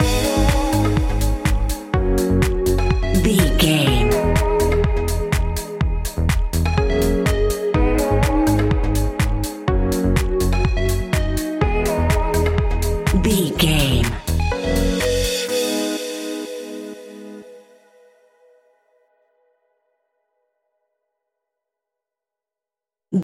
Aeolian/Minor
G#
groovy
uplifting
driving
energetic
repetitive
drum machine
synthesiser
piano
electro house
house music
synth leads
synth bass